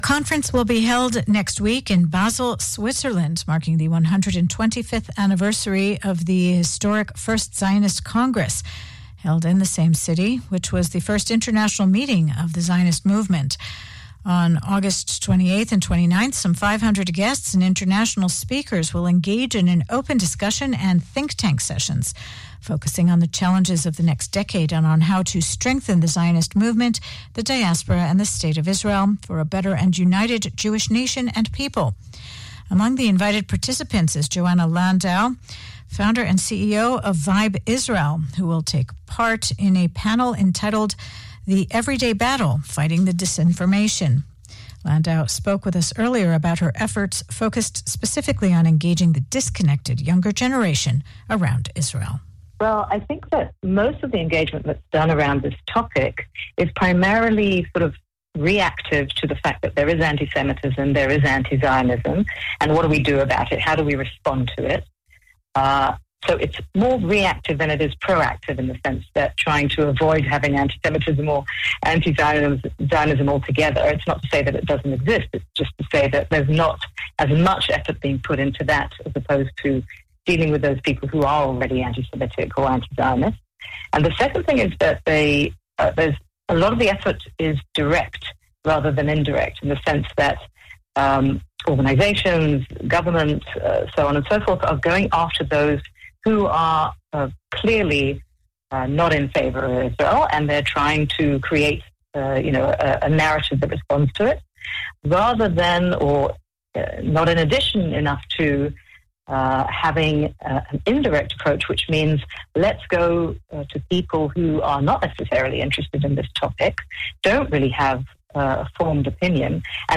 Interview on Kan Reka English Radio